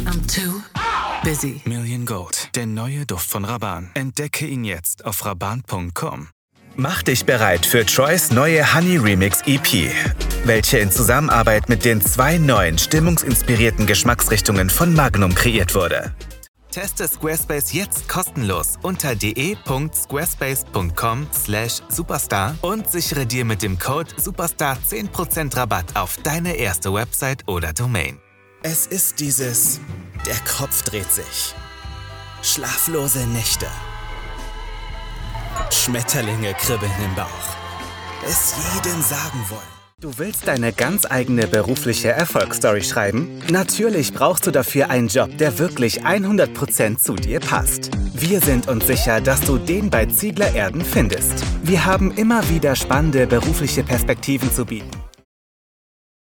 Young
Youthful
Soft